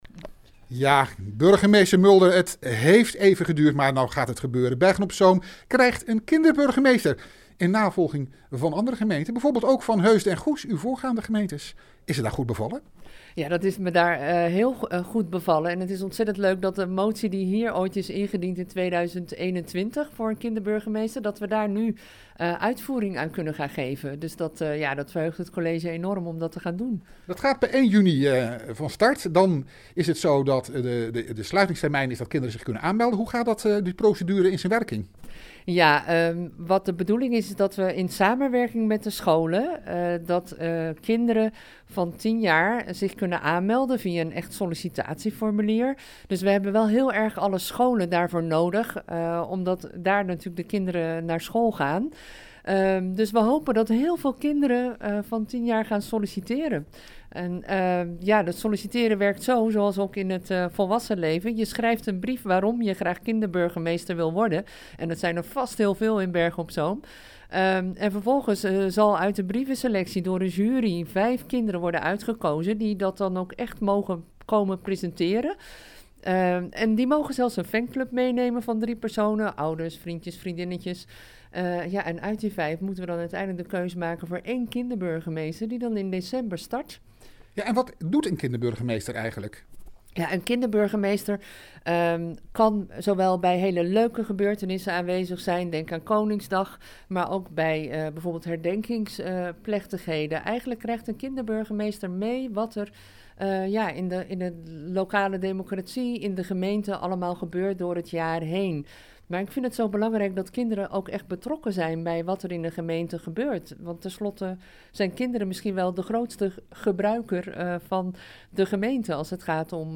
Audio: Burgemeester Margo Mulder over de kinderburgemeester.